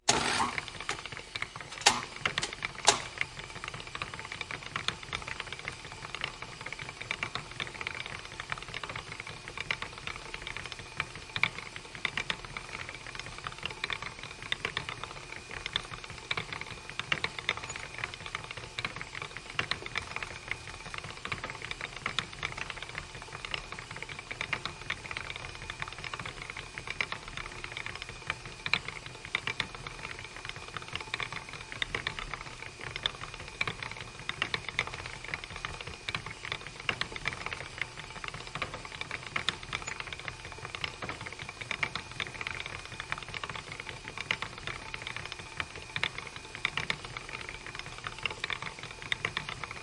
金属加工厂" 机器金属切割器磨辊小关闭4
描述：机器金属切割机研磨机滚筒小close4.flac
标签： 切割机 靠近小 机器 金属 粉碎机
声道立体声